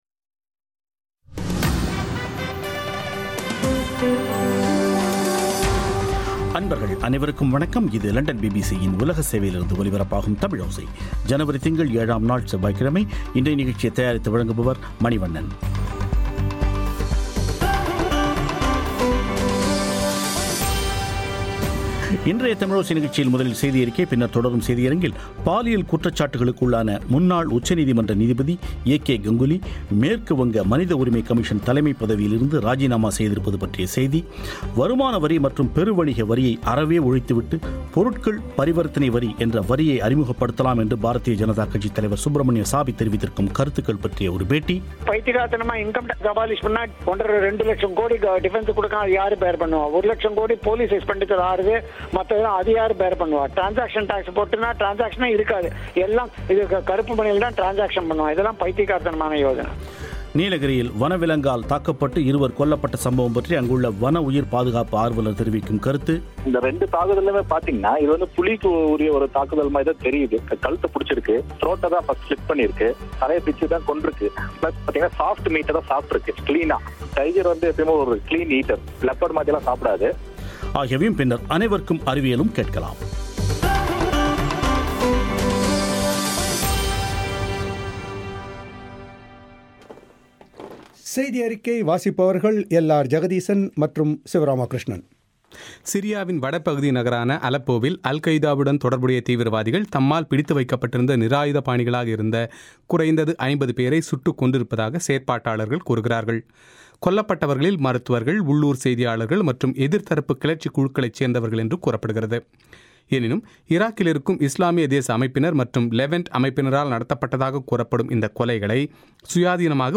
வருமான வரி மற்றும் பெரு வணிக வரியை அறவே ஒழித்துவிட்டு, பொருட்கள் பரிவர்த்தனை வரி என்ற வரியை அறிமுகப்படுத்தலாம் என்று பாஜக தலைவர் சுப்ரமணிய சுவாமி தெரிவித்திருக்கும் யோசனை பற்றி ஒரு பேட்டி